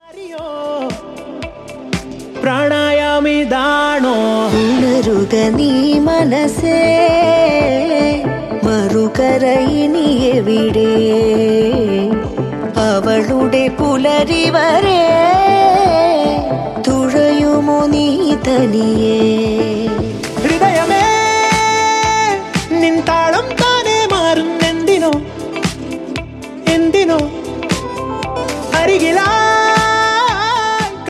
best flute ringtone download | love song ringtone
romantic ringtone download